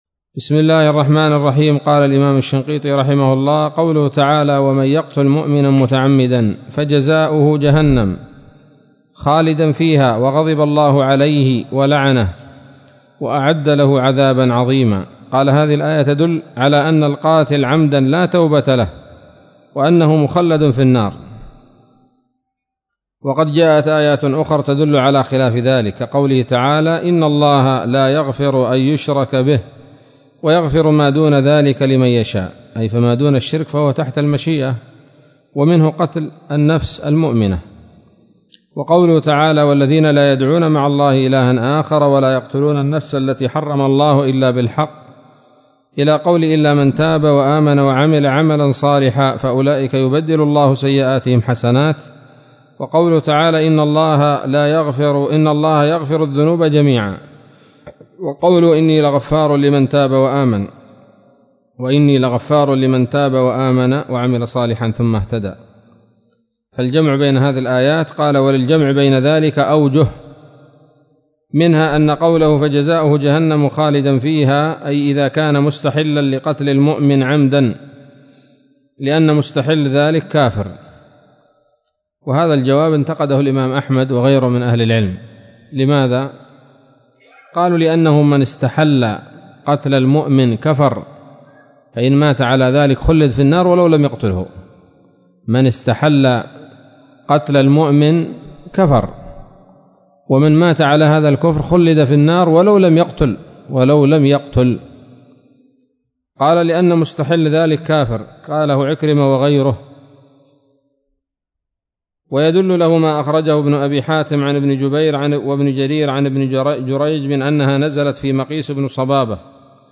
الدرس الثاني والثلاثون من دفع إيهام الاضطراب عن آيات الكتاب